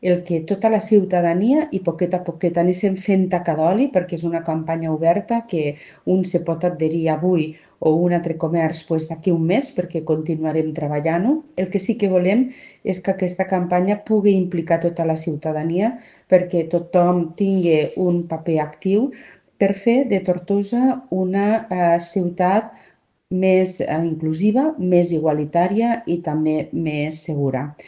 Segons Lleixà l’adhesió a la campanya és oberta ja que es treballa per conscienciar i implicar tota la ciutadania en la lluita contra les discriminacions, així com qualsevol altra forma de violència i fomentar la igualtat, la diversitat i el respecte cap a totes les persones…